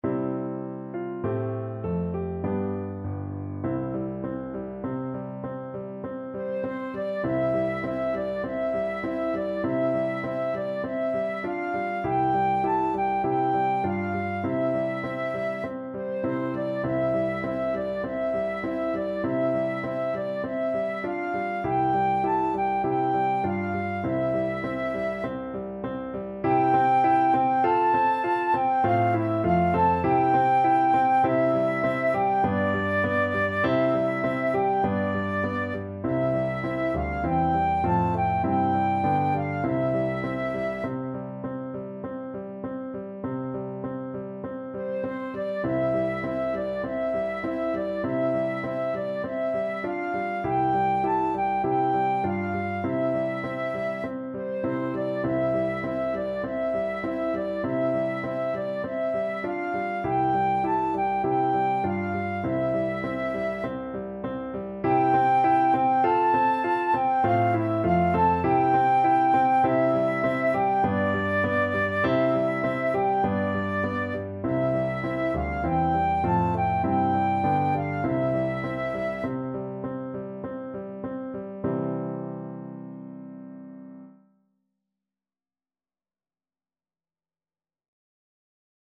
Christmas Christmas Flute Sheet Music Es ist fur uns eine Zeit angekommen
Flute
C major (Sounding Pitch) (View more C major Music for Flute )
Moderato
Traditional (View more Traditional Flute Music)